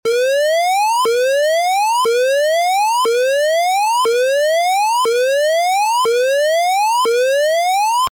Alarm Siren Sound Effect 03 Sound Button - Free Download & Play